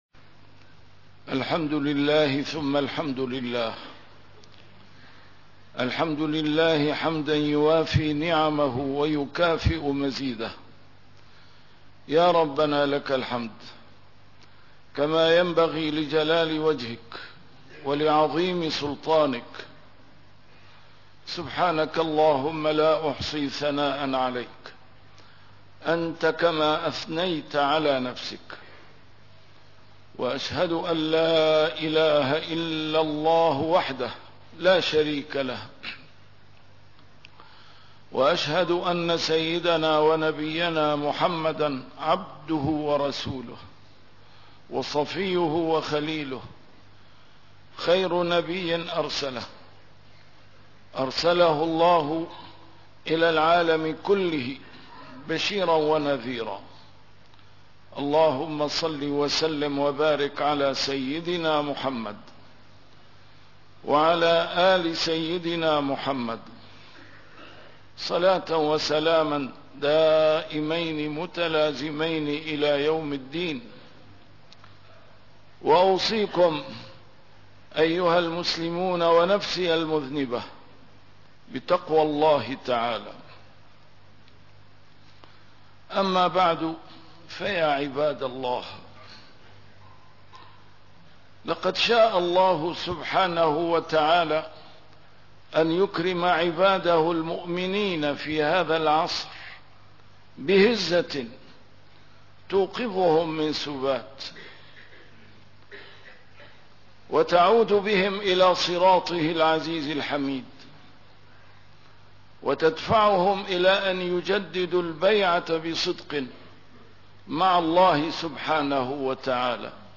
A MARTYR SCHOLAR: IMAM MUHAMMAD SAEED RAMADAN AL-BOUTI - الخطب - بغداد لم تسقط والإسلام لن يهزم